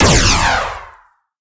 alien_laser_02.ogg